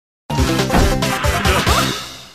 banjo-kazooie-collect-extra-life_25877.mp3